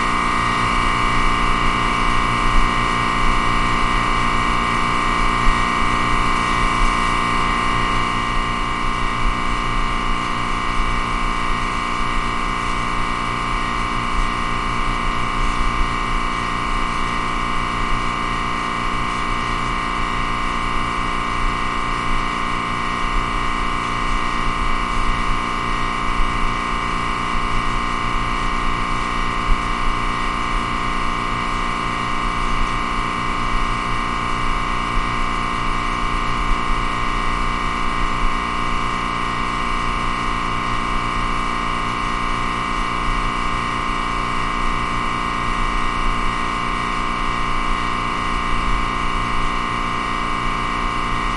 随机" 压缩机和大风扇在地下室的声音很大
描述：压缩机和大风扇在basement.flac大声
标签： 响亮 风扇 地下室 压缩机
声道立体声